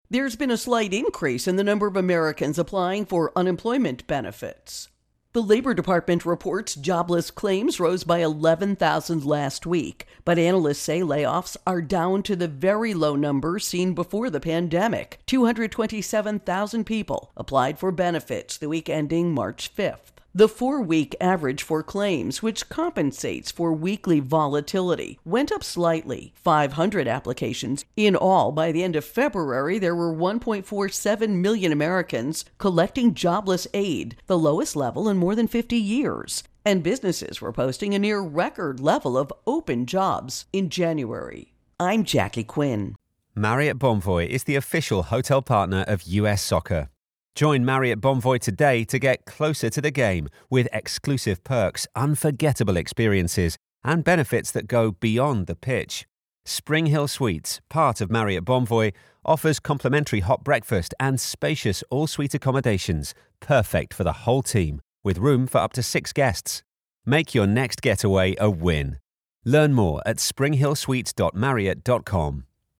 Unemployment Benefits Intro and Voicer